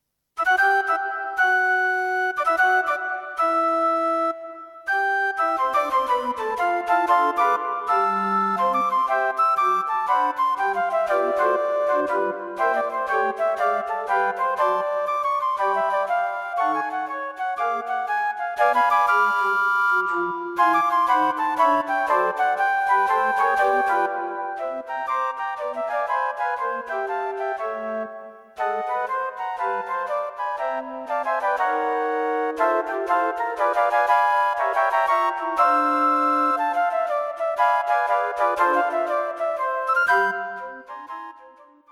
Flute and Piano
A set of pieces in a light style, from swing to mellow jazz.
Arranged for two Flutes, Alto Flute, and Bass Flute.